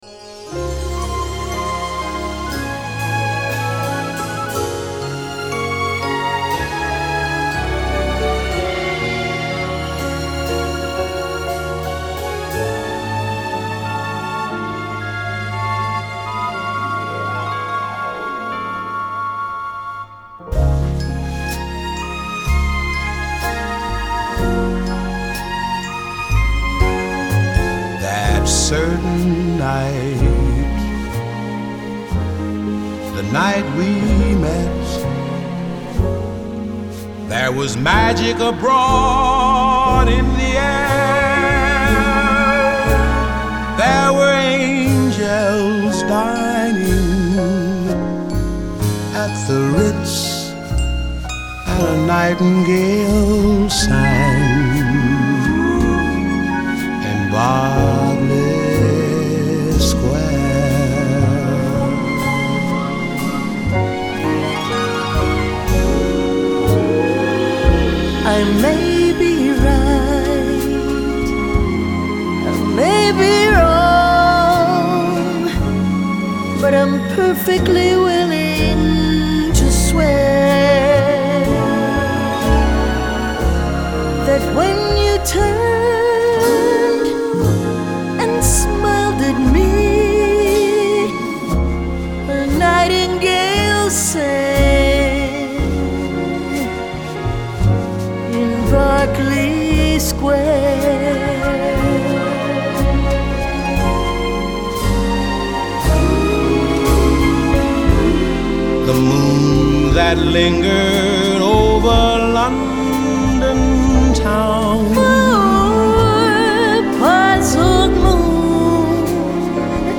Genre : Ambient, New Age